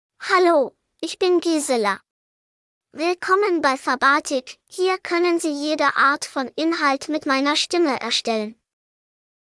Gisela — Female German (Germany) AI Voice | TTS, Voice Cloning & Video | Verbatik AI
Gisela is a female AI voice for German (Germany).
Voice sample
Female
Gisela delivers clear pronunciation with authentic Germany German intonation, making your content sound professionally produced.